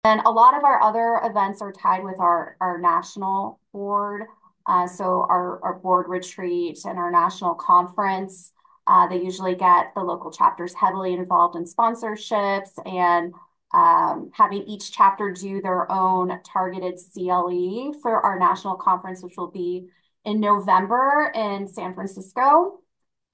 Interview Clips